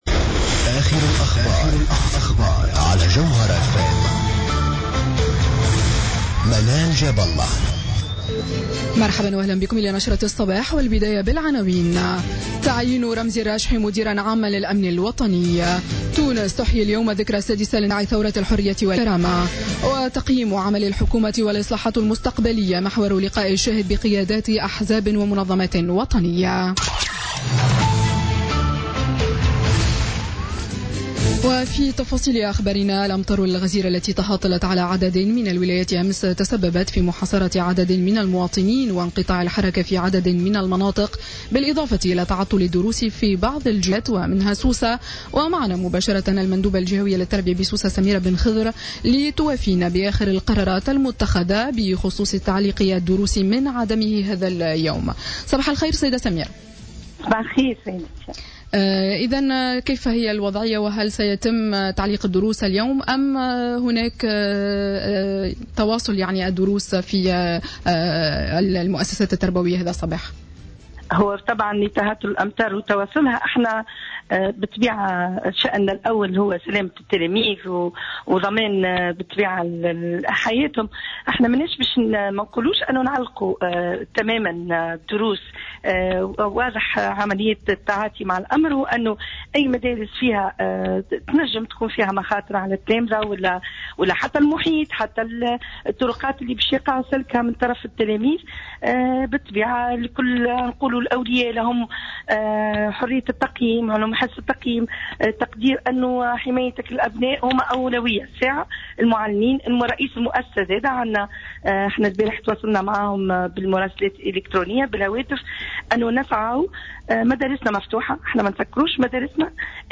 نشرة أخبار السابعة صباحا ليوم السبت 17 ديسمبر 2016